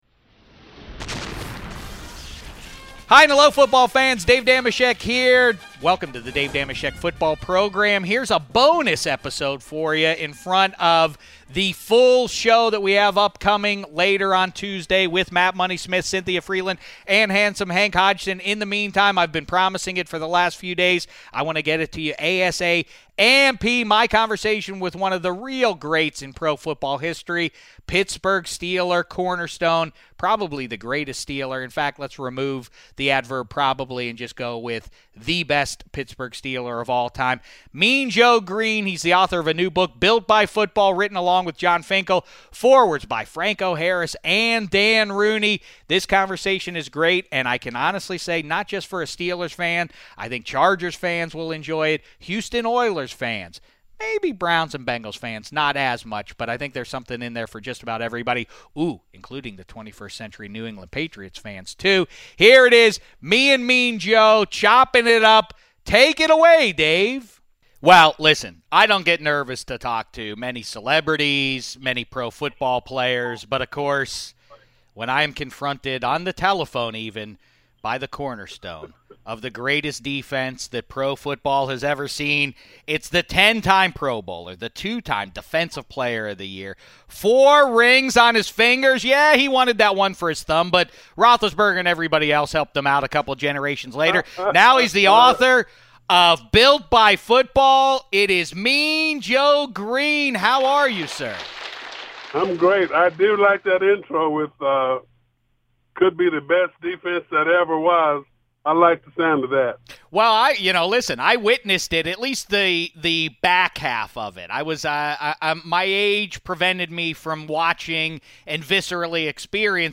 DDFP 545: 'Mean' Joe Greene interview